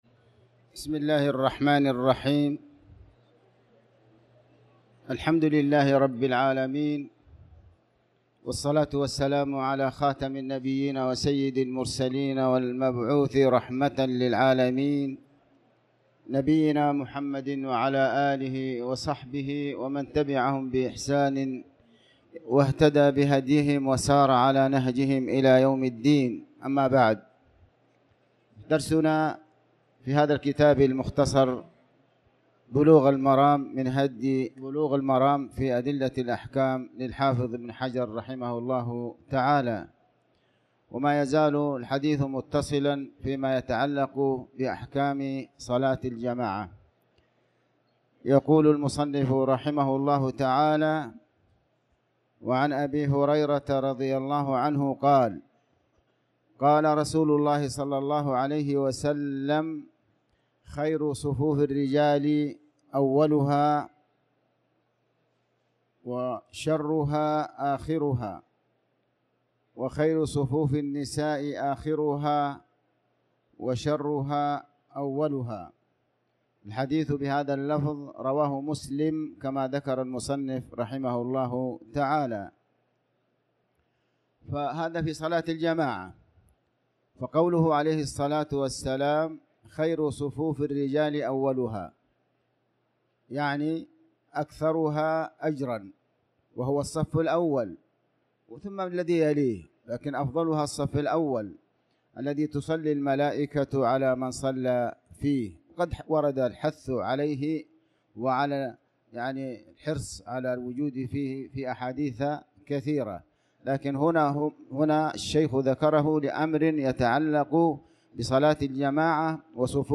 تاريخ النشر ١٣ شعبان ١٤٤٠ هـ المكان: المسجد الحرام الشيخ